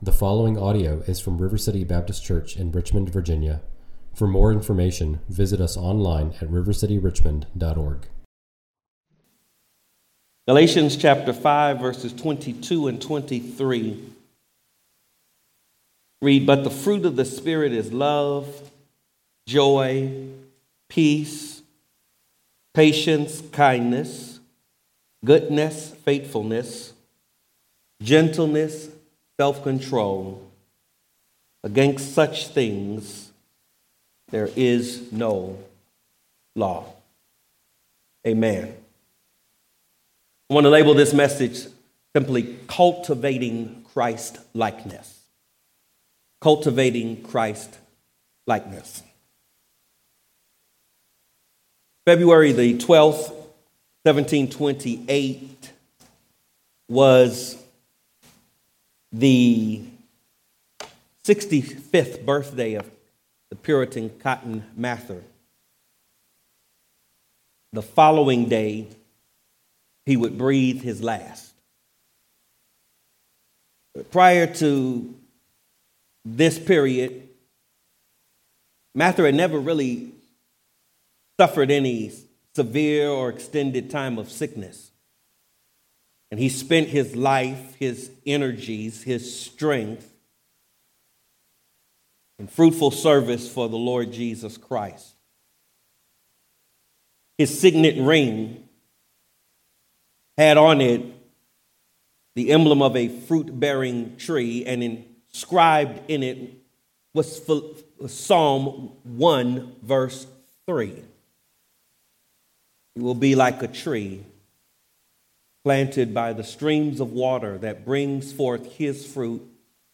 at River City Baptist Church, a new congregation in Richmond, Virginia.